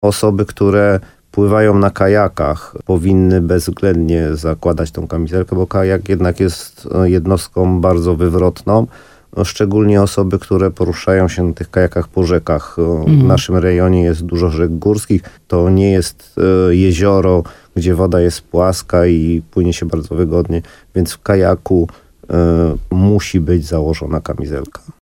Tak radził w programie Słowo za Słowo na antenie RDN Nowy Sącz